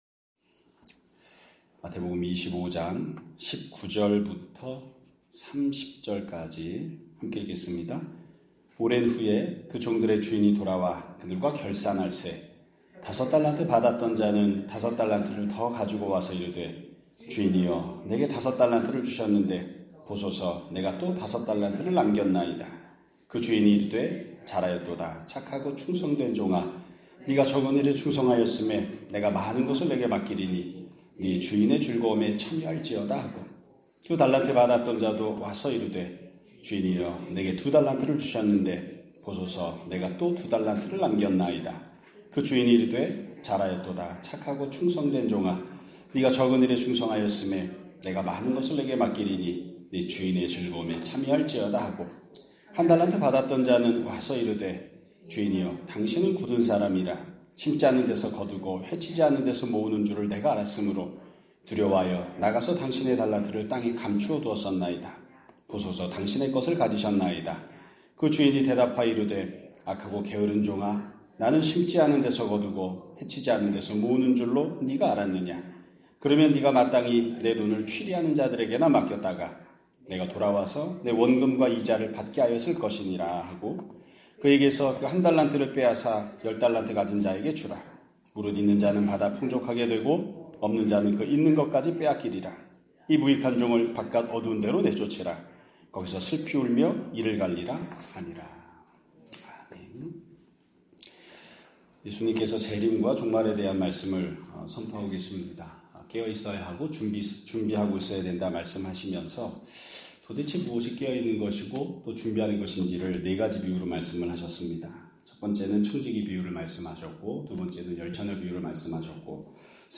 2026년 3월 20일 (금요일) <아침예배> 설교입니다.